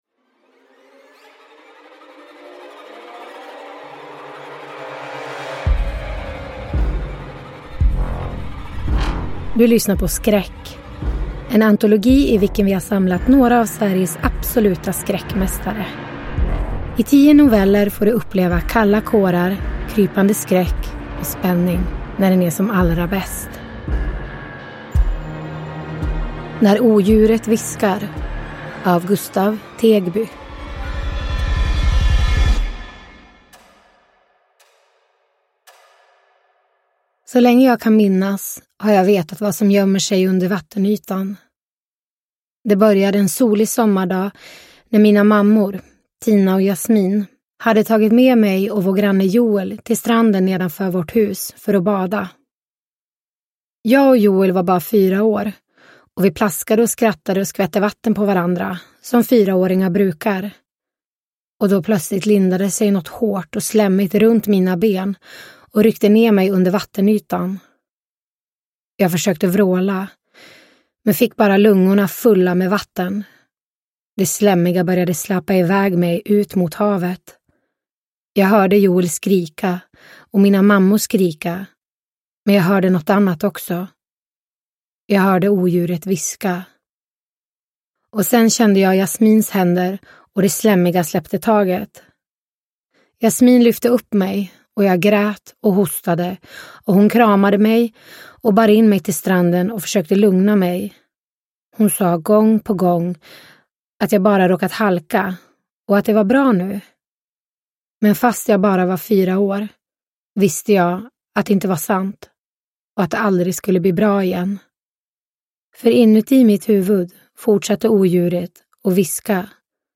Skräck - När odjuret viskar – Ljudbok – Laddas ner